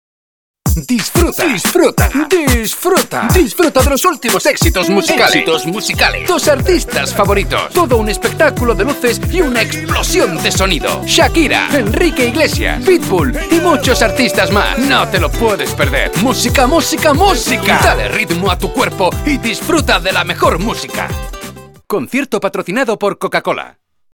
Actor de doblaje con 26 años experiencia
Neuman U87ai TlAudio 5051 Focusrite soundcard Protols 12,8,3
Sprechprobe: eLearning (Muttersprache):